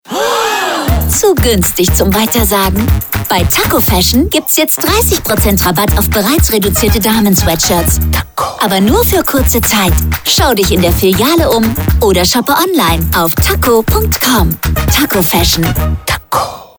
Sound Logo im Audio Spot